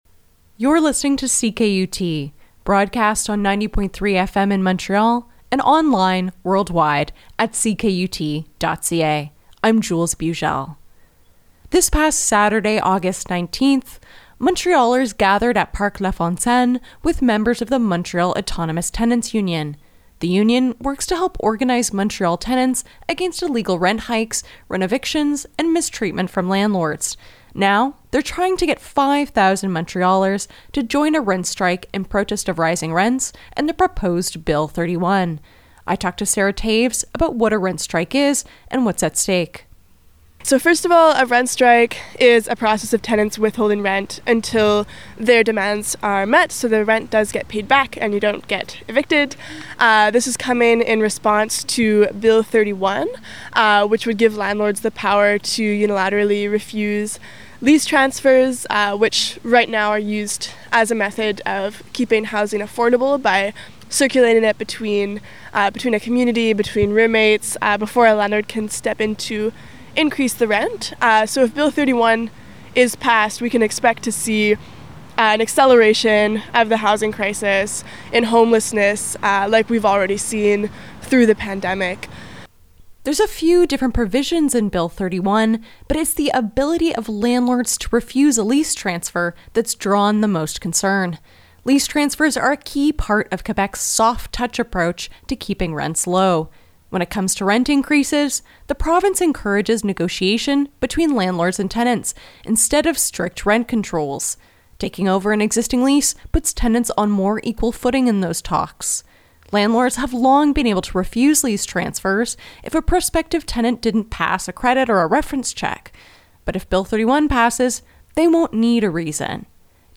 Montrealers gathered at Parc Lafontaine with members of the Montreal Autonomous Tenants' Union Last Saturday (Aug. 19).
8-21-rent-strike-meeting.mp3